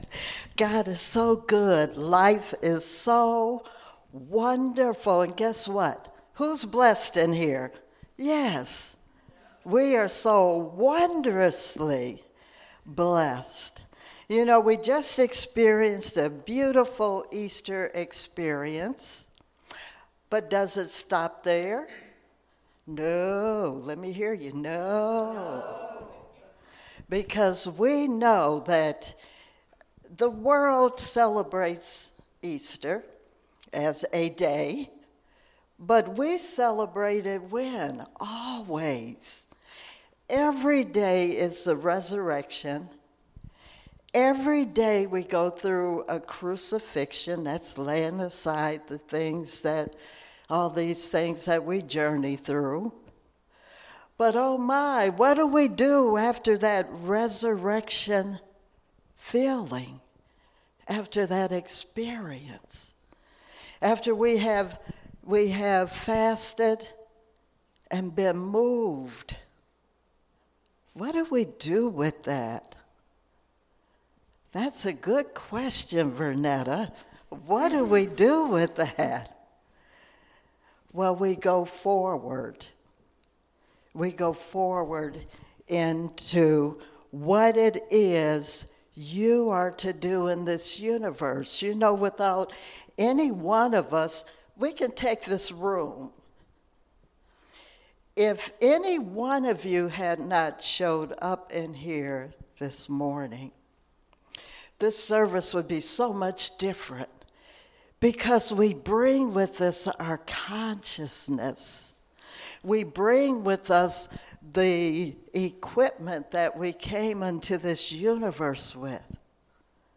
Sermon Mp3s